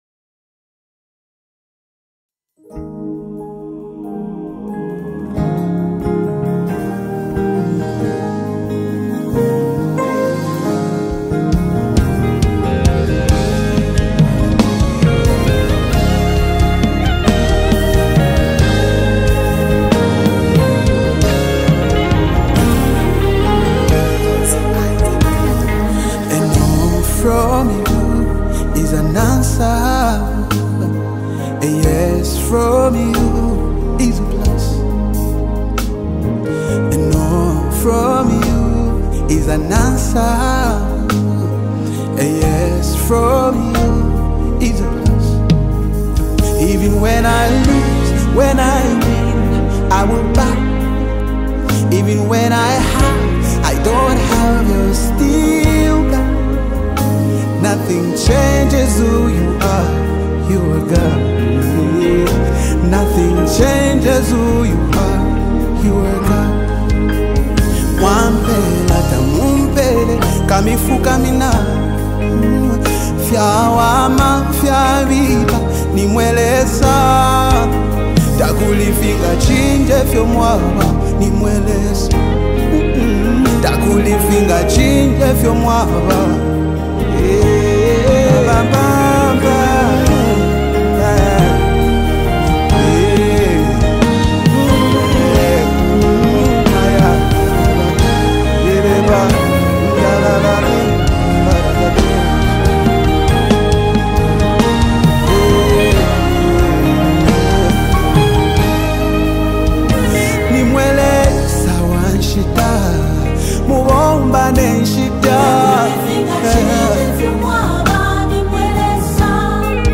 uplifting worship anthem
With its soul-stirring melody and anointed message
deep worship